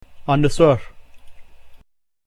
Its sound is best represented as being the sound of the combination AL in the English word PALM. N is also vaguely equivalent to the combination "NGG" in English.